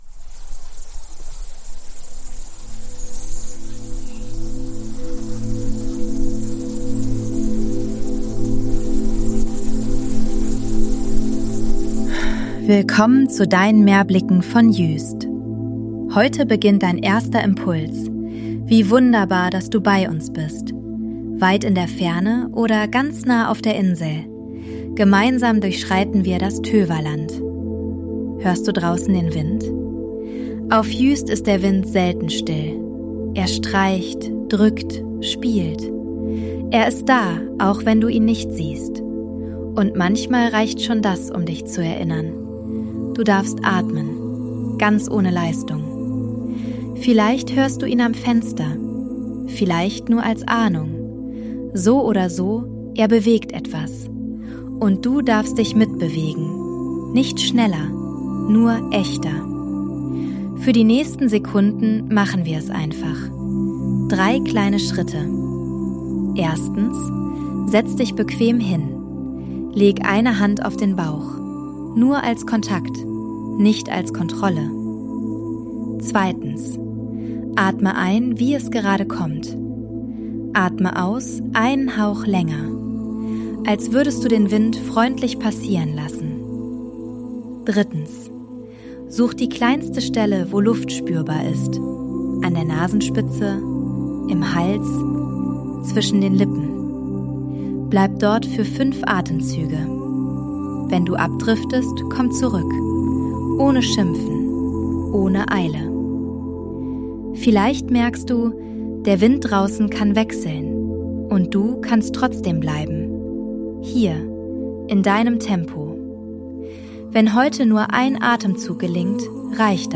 Kurverwaltung Juist Sounds & Mix: ElevenLabs und eigene Atmos